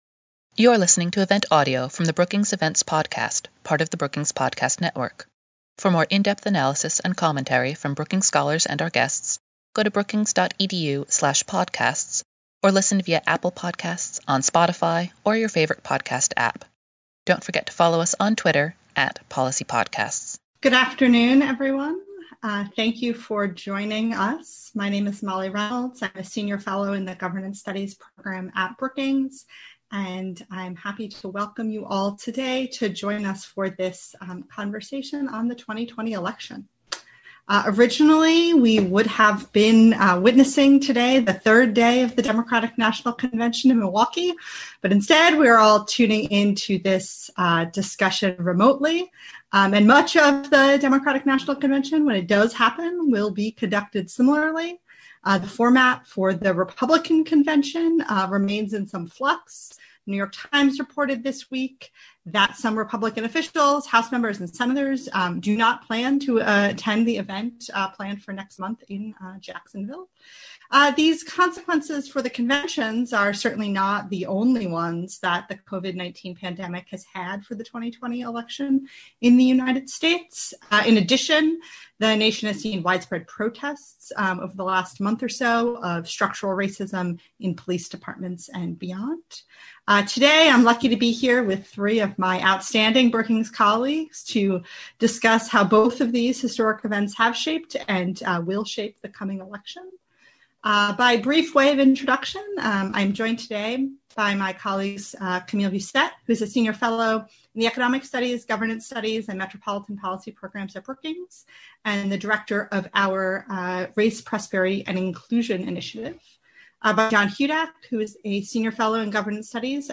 On July 15, Governance Studies at Brookings hosted a webinar examining the 2020 election landscape.